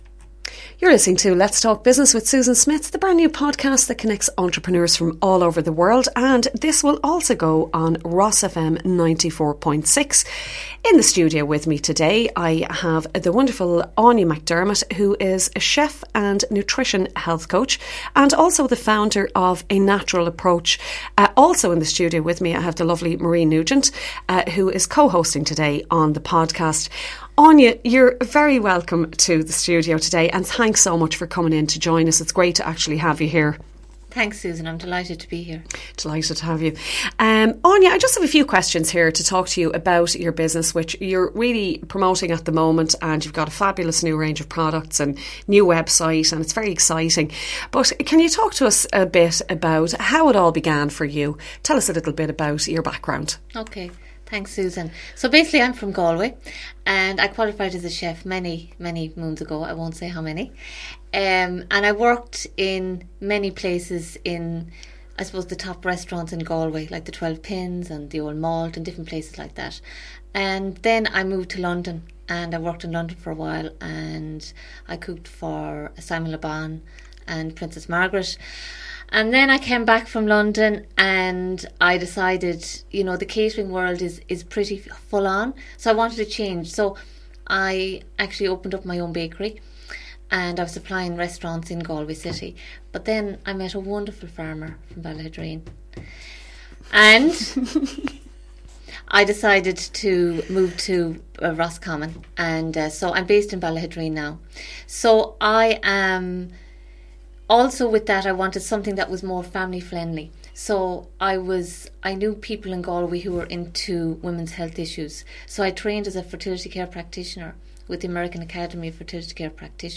Interview - RosFM 94.6